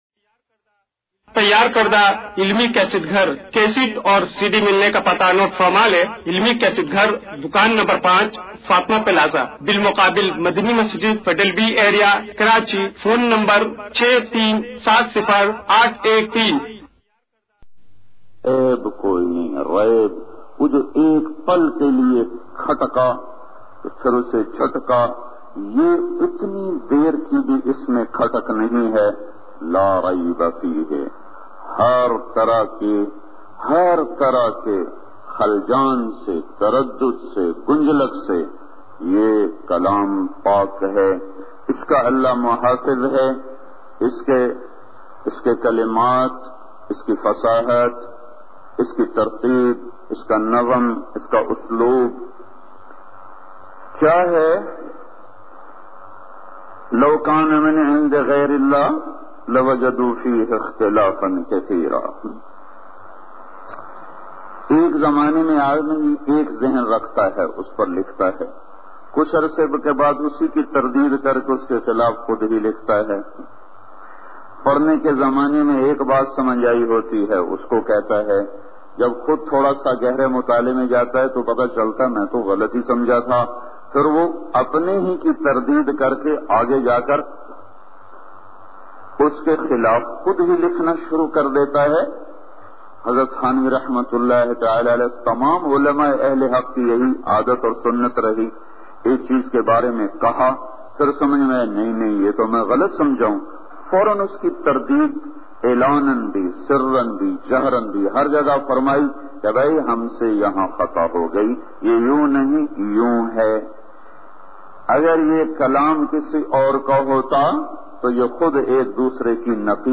ALLAH KA ILM part 1 bayan mp3